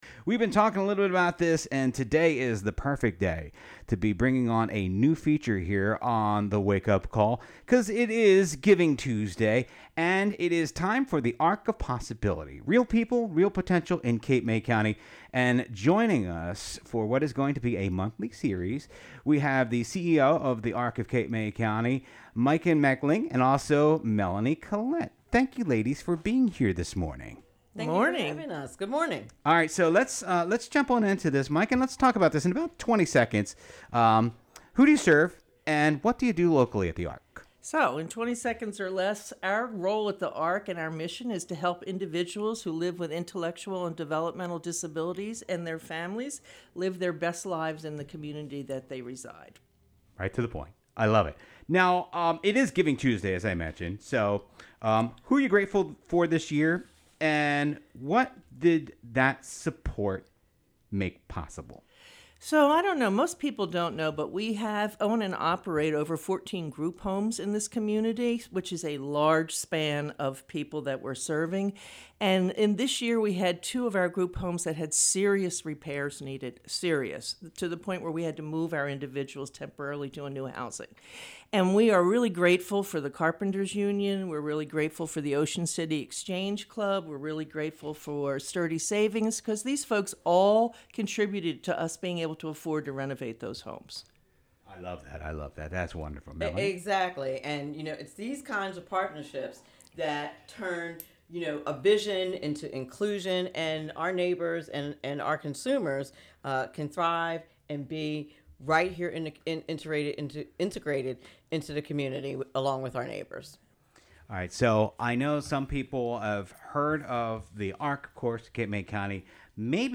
“The Arc of Possibility” Radio Segment Debuts on 98.7 The Coast!